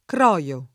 croio [ kr 0L o ]